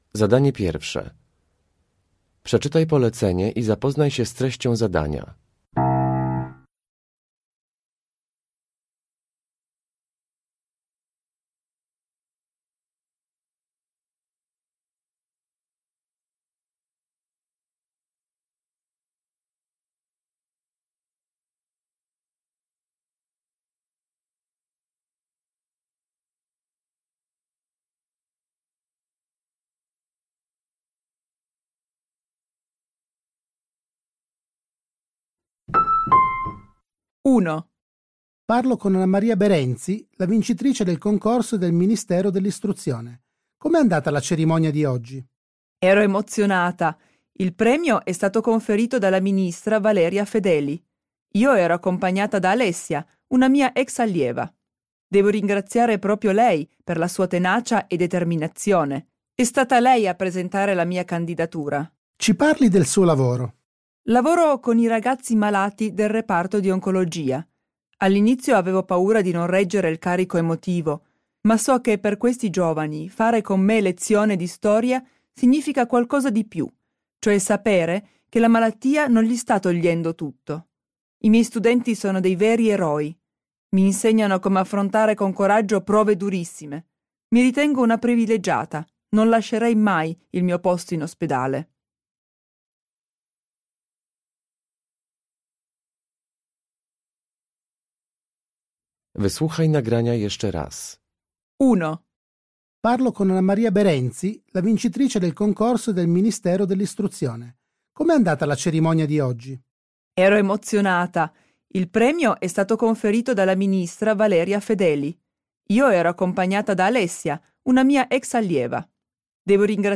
La donna intervistata è
Uruchamiając odtwarzacz z oryginalnym nagraniem CKE usłyszysz dwukrotnie wywiad z trenerem sztuki walki Krav Maga.